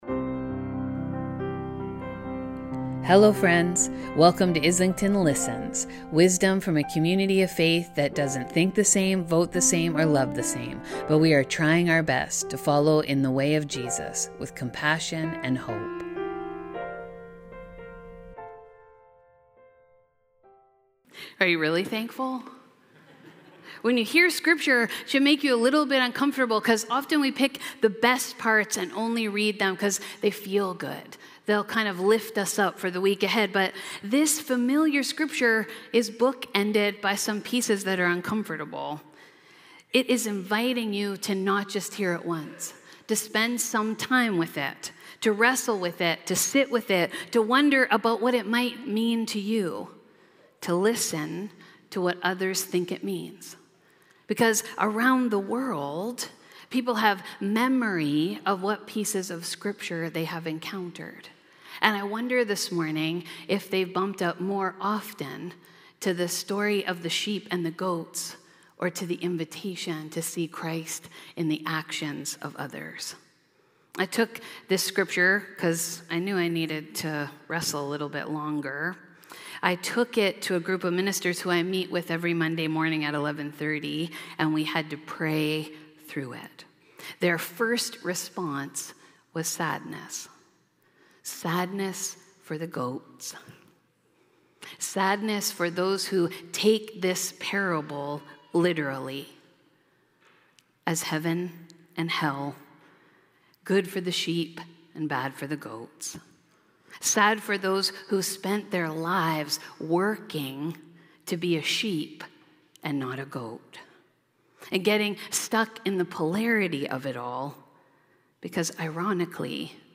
On Sunday, October 6, 2024, Islington United Church recognized World Communion Day.